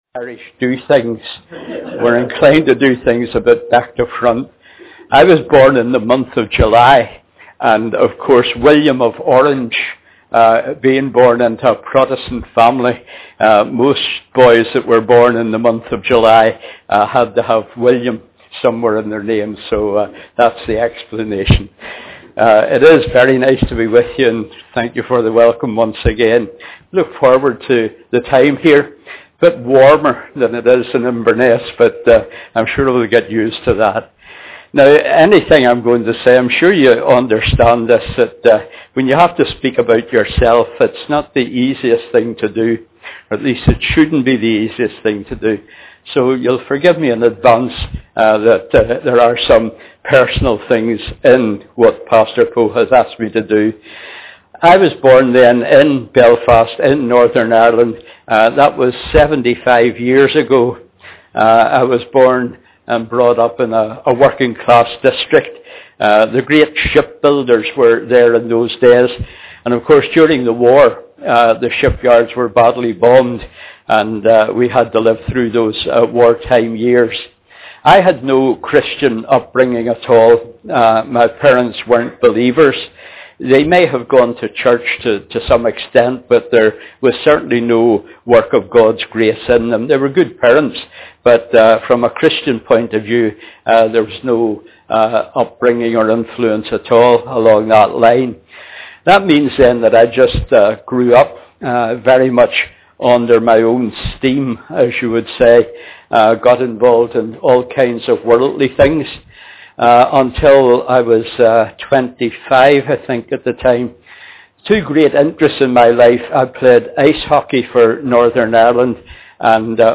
Preached on the 20th of August 2008.